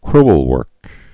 (krəl-wûrk)